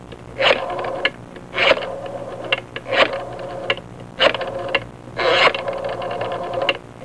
Dialing A Phone Sound Effect
Download a high-quality dialing a phone sound effect.
dialing-a-phone.mp3